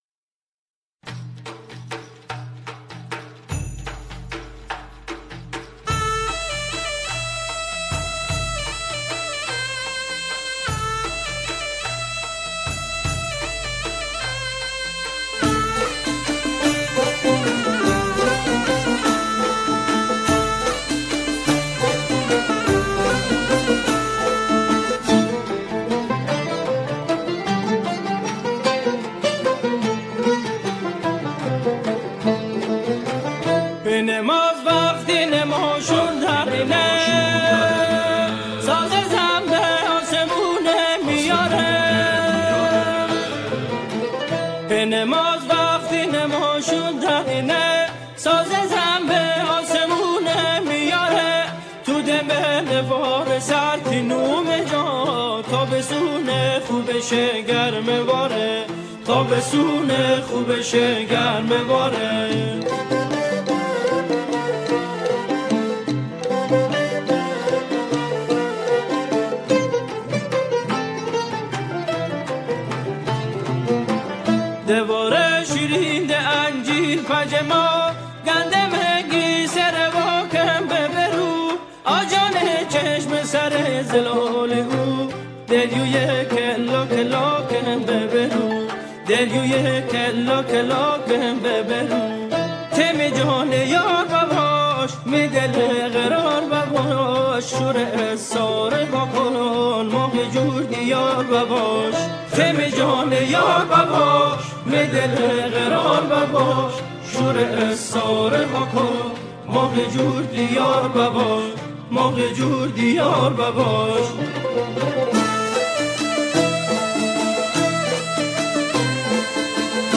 همخوانی شعری با گویش مازندرانی